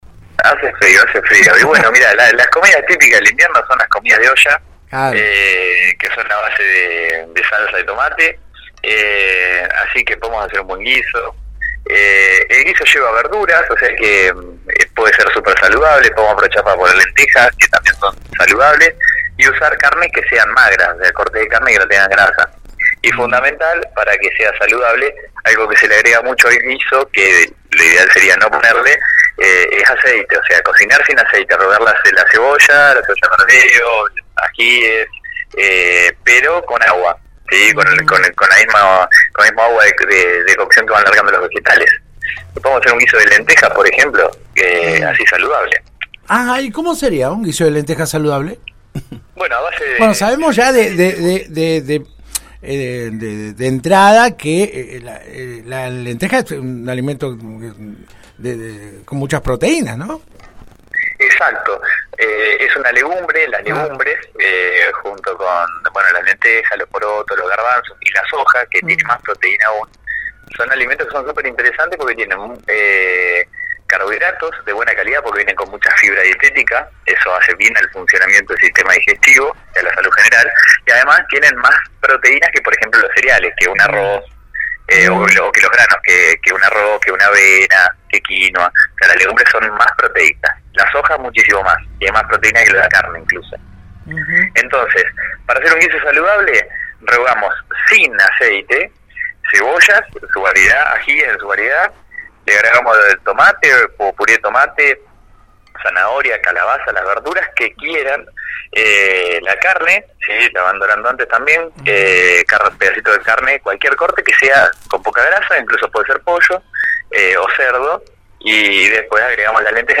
La intensa ola de frío nos lleva a alimentarnos con comida calentita que a veces son altas en calorías y nos lleva a tenerle miedo a la balanza. Por ese tema, el especialista en Nutrición habló sobre el tema en la 91.5 y nos dejó una serie de consejos valiosos para comer bien en invierno sin engordar.